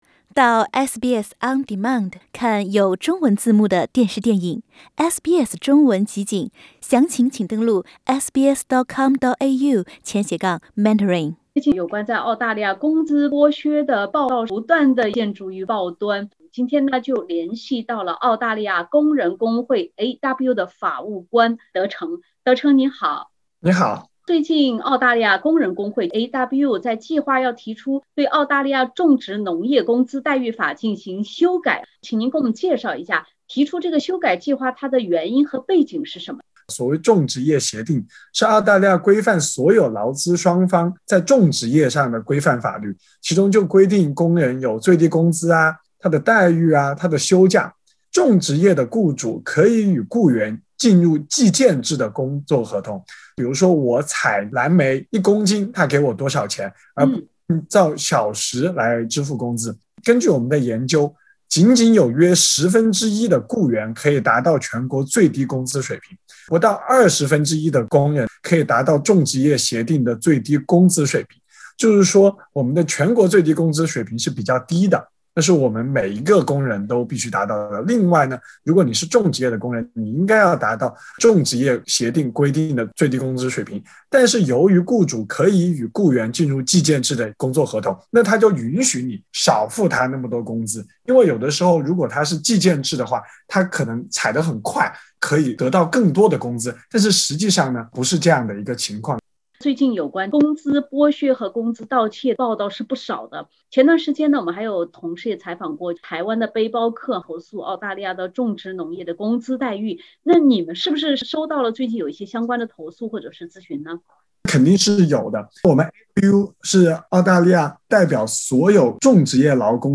（点击封面图片，收听完整对话） 近期有关澳大利亚工资剥削、工资盗窃的报道频频曝光，澳大利亚工人工会AWU也在近日向公平工作委员会申请修改澳洲种植业劳资协定（Horticultural Award）。